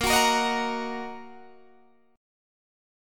Listen to Bbsus4#5 strummed